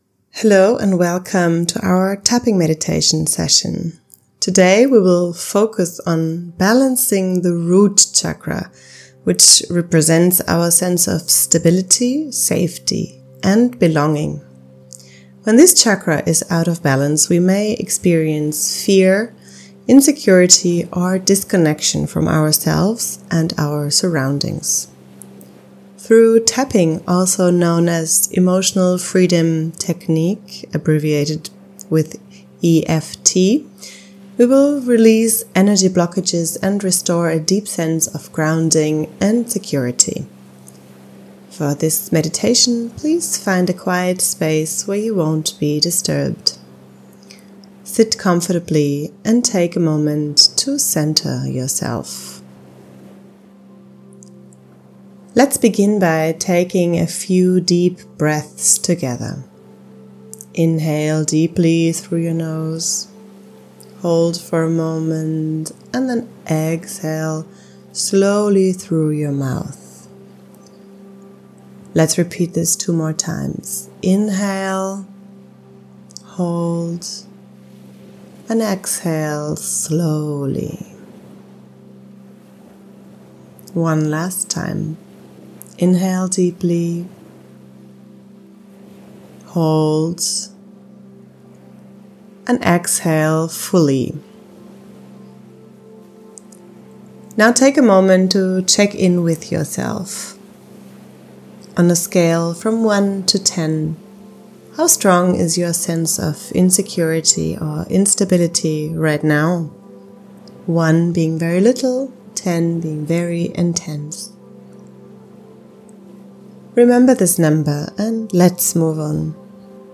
Root-Chakra-Tapping-Meditation.mp3